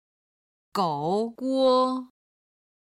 今日の振り返り！中国語発声
01-gouguo.mp3